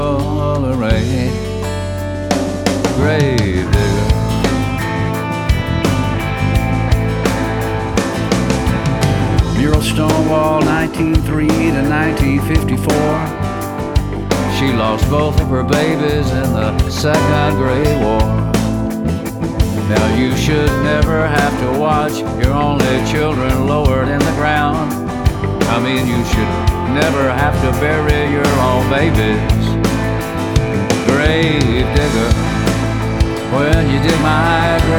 Жанр: Поп музыка / Рок / Кантри